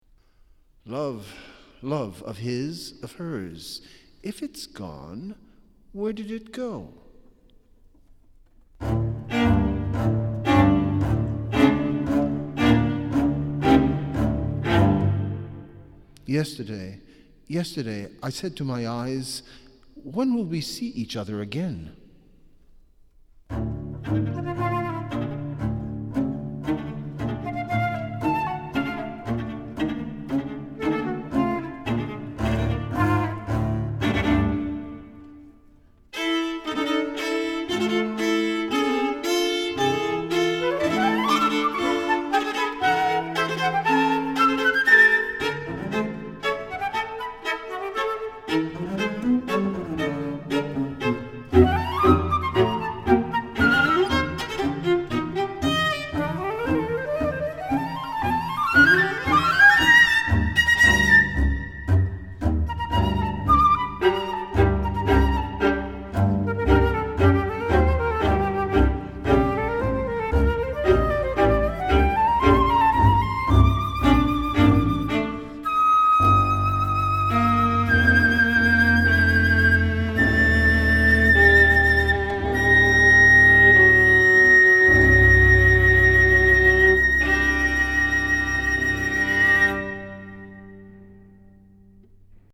flute, viola, double bass
with narrator (optional)